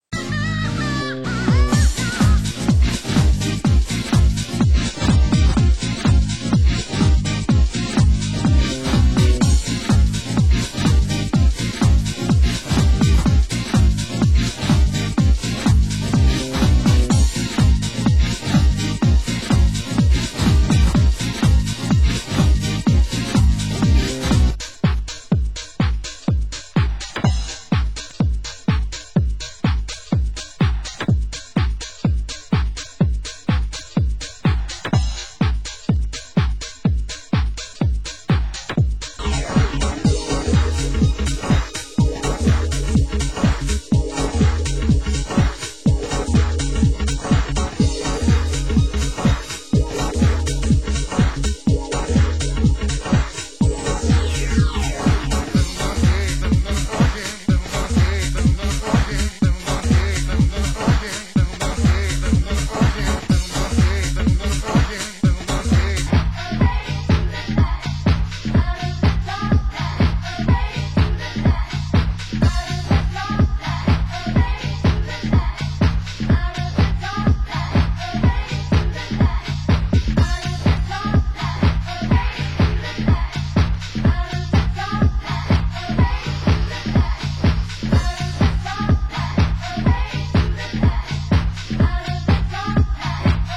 Genre: UK Garage